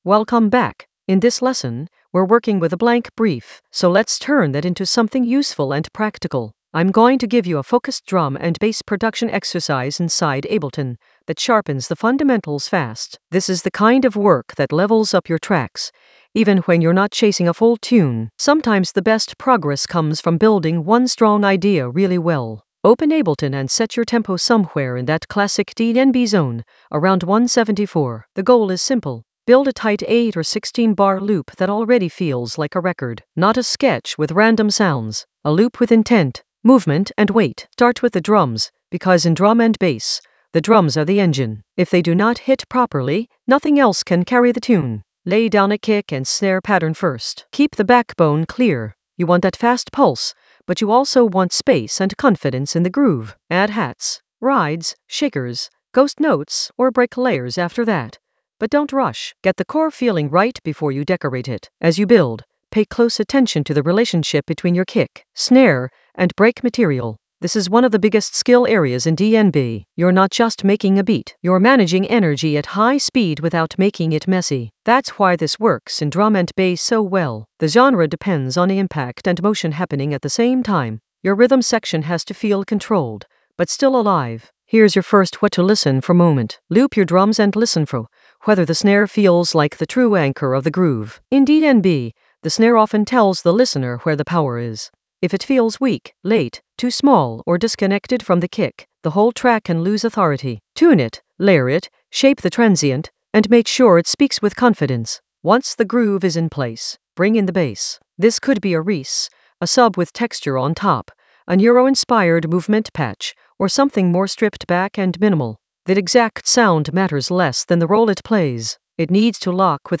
An AI-generated beginner Ableton lesson focused on MetalHeadz sub basslines that crush in the Basslines area of drum and bass production.
Narrated lesson audio
The voice track includes the tutorial plus extra teacher commentary.